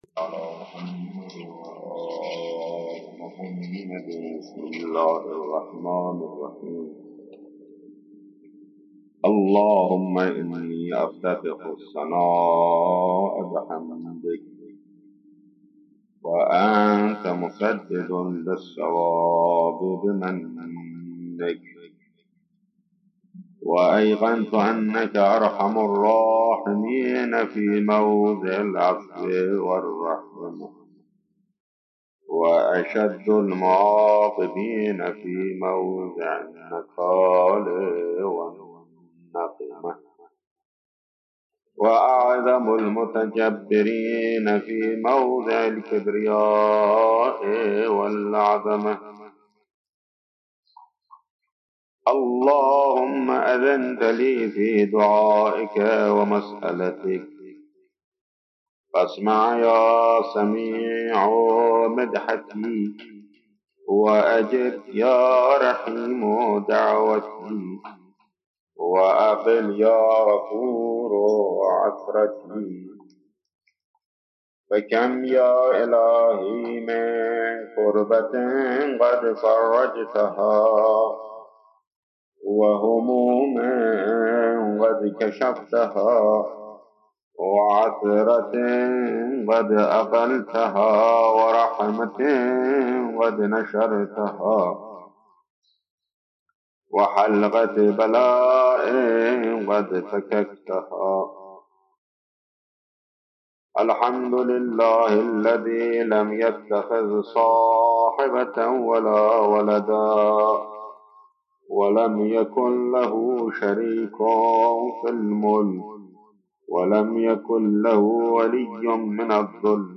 قرائت دعای افتتاح